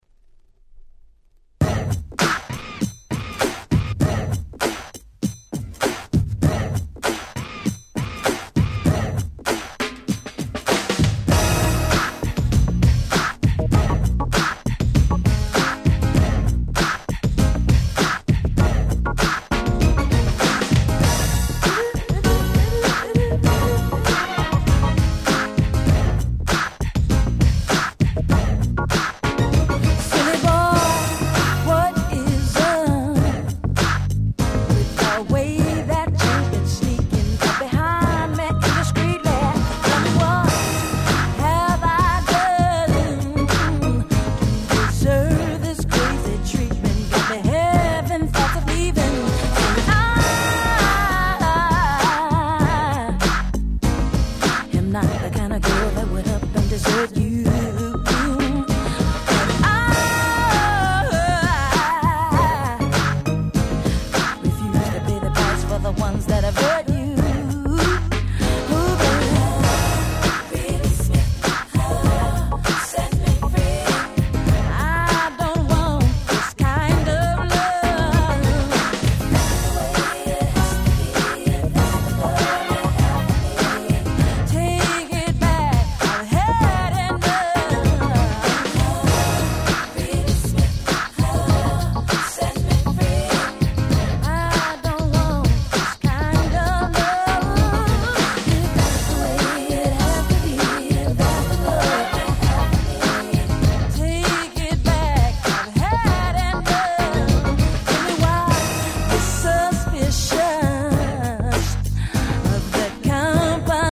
92' Nice New Jack Flava R&B !!
NJS ニュージャックスウィング ハネ系